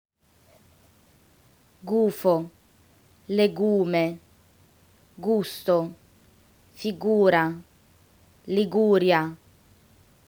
In Italian, G always gets a hard sound when followed by U. However, don’t forget to say the “U” as well!
In other words, “G” and “U” are pronounced as separate letters
POPULAR WORDS WITH “GU + CONSONANT